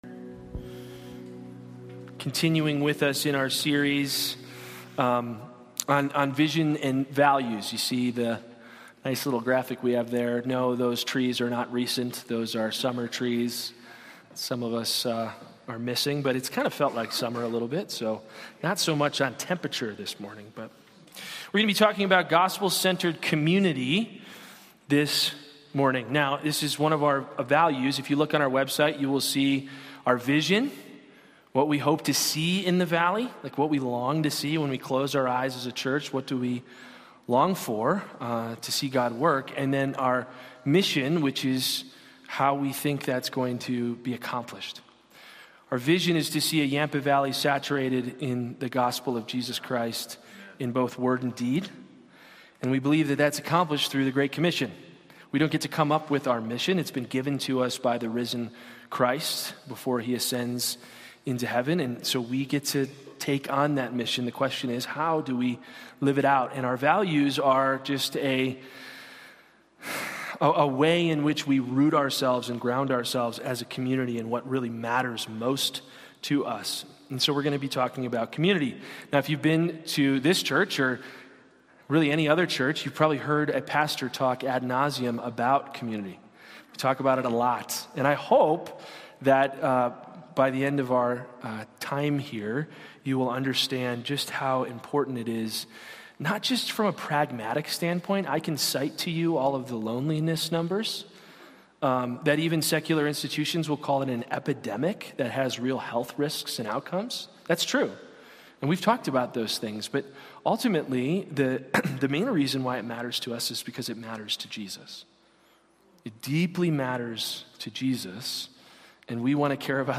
Sermons | Anchor Way Church